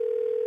ring.ogg